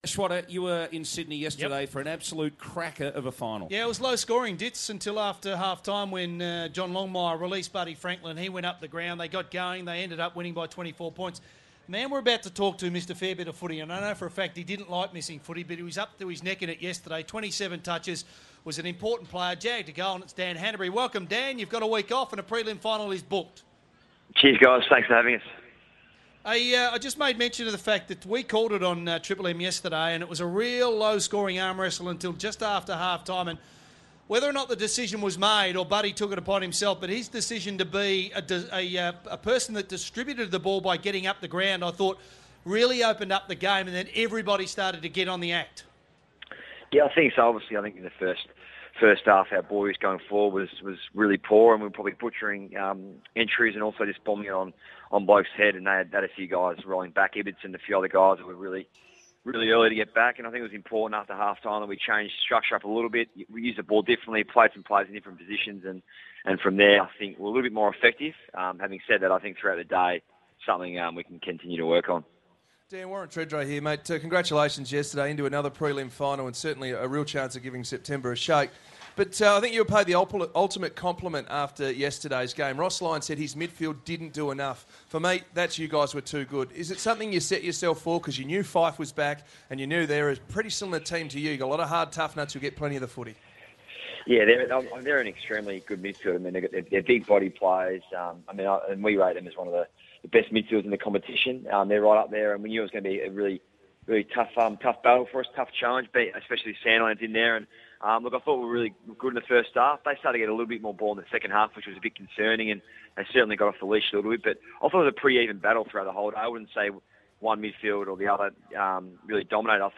Midfielder Dan Hannebery appeared on Triple M's footy coverage on Sunday September 7, 2014